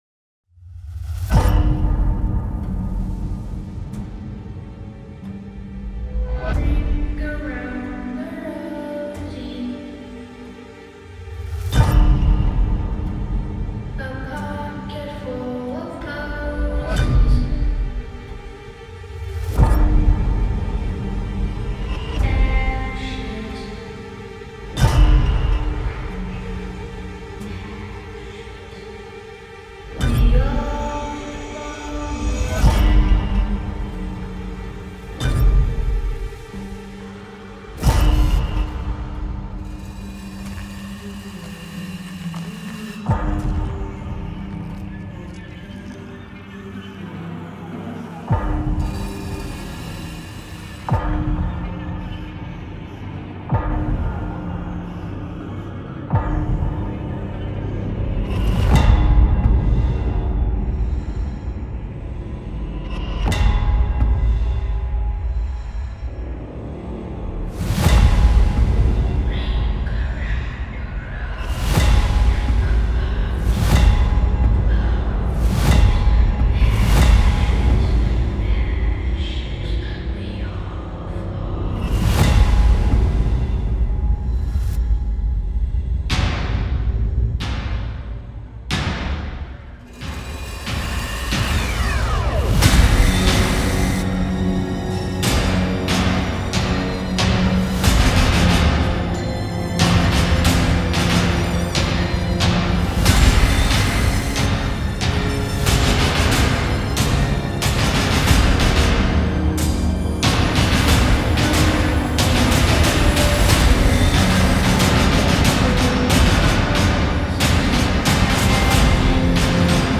Creepy Nursery Rhymes